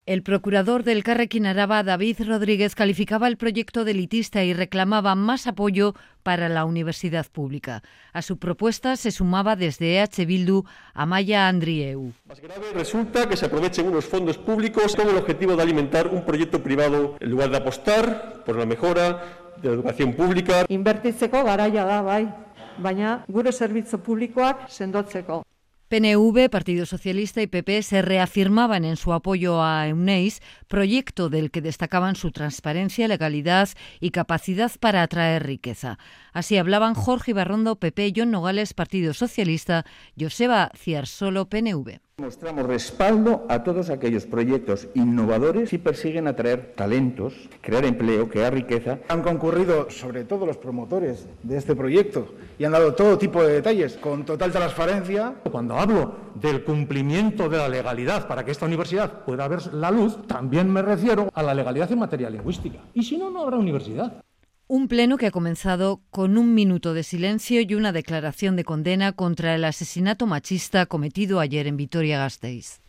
Debate esta mañana en las Juntas Generales de Álava sobre la futura universidad Euneiz, promovida por el grupo Baskonia-Alavés.